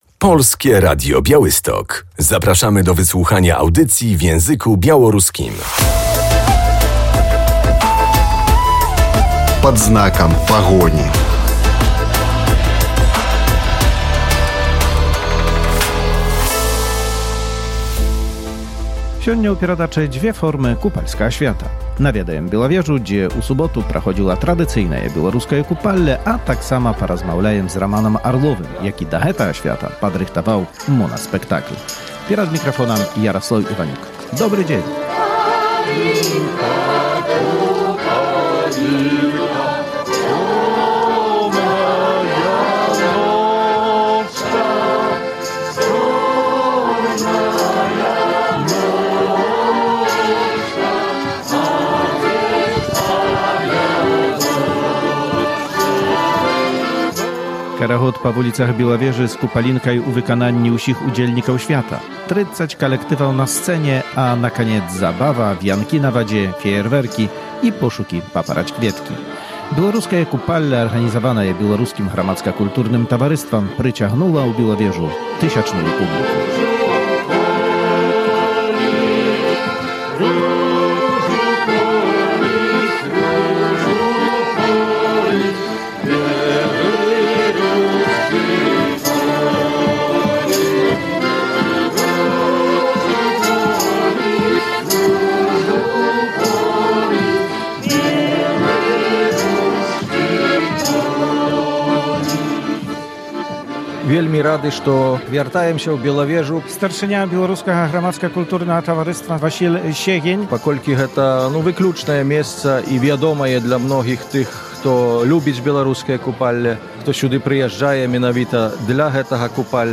Korowód ulicami miasteczka, Kupalinka śpiewana przez wszystkich uczestników święta, 30 zespołów na scenie a na koniec zabawa taneczna, puszczanie wianków, fajerwerki i szukanie kwiatu paproci – tradycyjne białoruskie Kupalle organizowane przez Białoruskie Towarzystwo